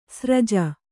♪ sraja